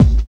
45 KICK 5.wav